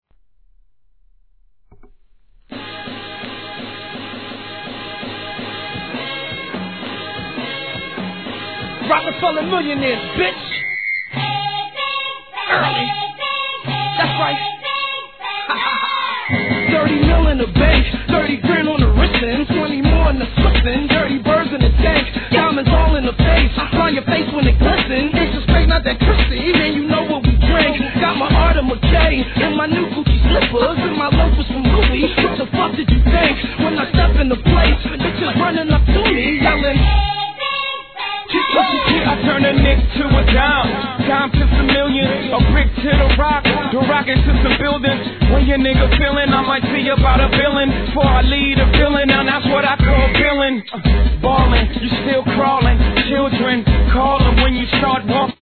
12inch
HIP HOP/R&B